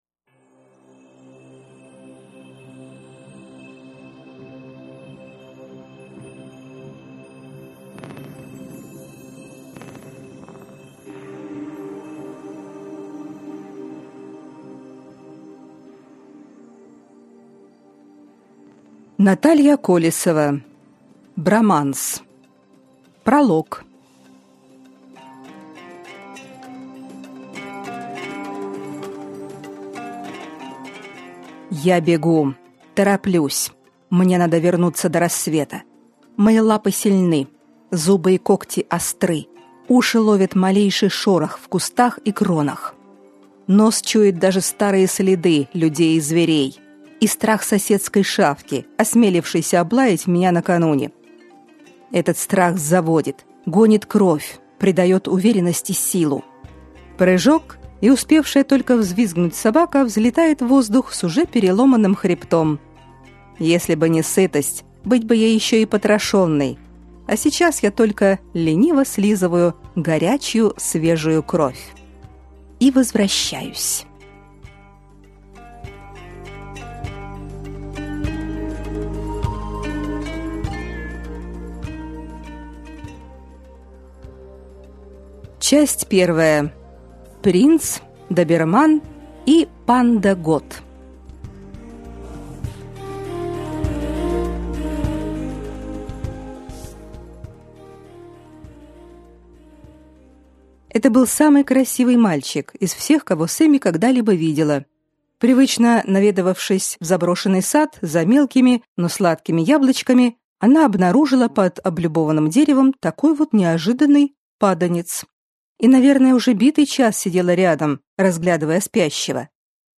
Аудиокнига Броманс | Библиотека аудиокниг